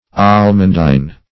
Almondine \Al"mon*dine\, n.